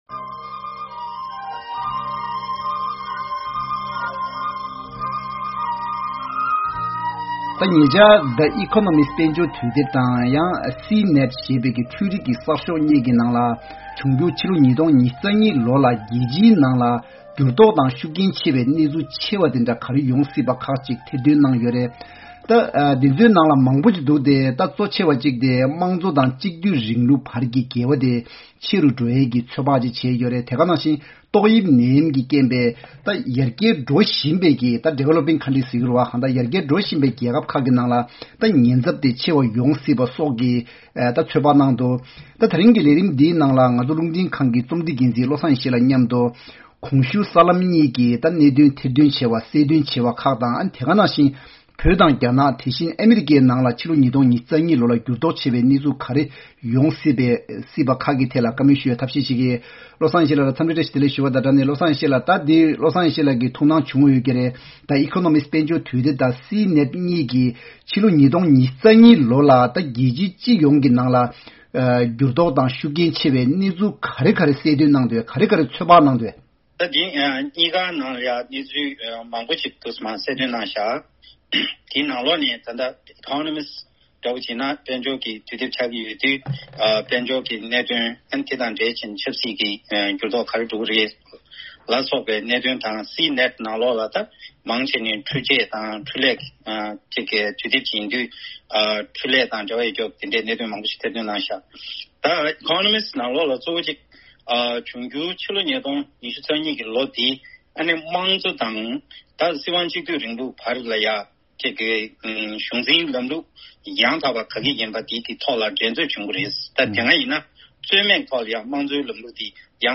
༄༅། བདུན་ཕྲག་འདིའི་བགྲོ་གླེང་མདུན་ཅོག་ལས་རིམ་གྱིས་ལོ་གསར་པའི་ནང་བོད་དང་རྒྱ་ནག ཨ་རི་བཅས་ཀྱི་ནང་འགྱུར་བ་ཆེ་བའི་གནས་ཚུལ་གང་ཡོང་སྲིད་པའི་ཐད་གླེང་མོལ་བྱས་ཡོད།